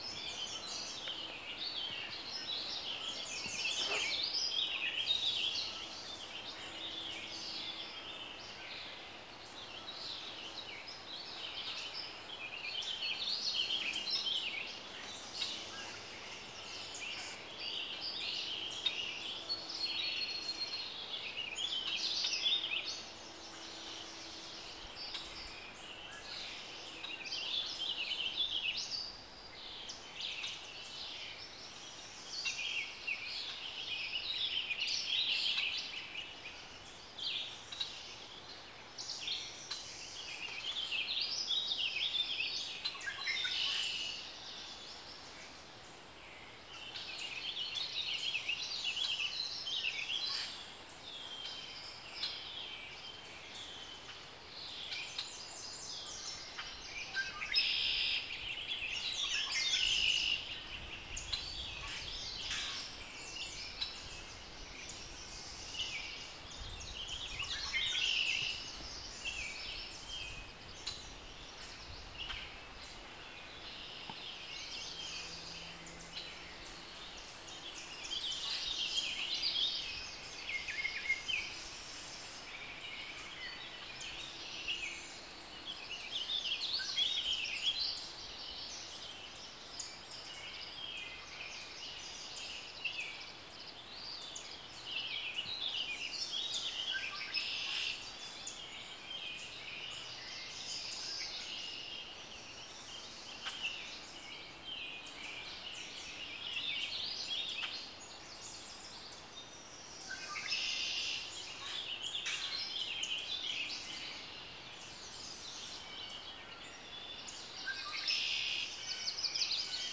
As I revisit experiences throughout my memory book, this one is from the morning of may 24, 2022. Here we are at the Marguerite Youville Wildlife Refuge at the Saint-Bernard island, annexed  to the municipality of Châteauguay.
Here you can experience a reverberating soundworld where three warbling vireos stand out, as well as American redstarts, yellow and chestnut-sided warblers, Baltimore orioles, common grackles, red-bellied woodpeckers, starlings, an Eastern woodpeewee and a robin further off. More occasionally appearing  birds include a northern flicker, tree swallows and a red-eyed vireo, as well as occasional sounds of birds of the surrounding marshes like Canada Geese. In the second recording, red-winged blackbirds  and a great crested flycatcher make themselves heard, as well as that same red-eyed vireo we hear at the end of the first recording.
We hear especially blackpole ones that permeate both recordings.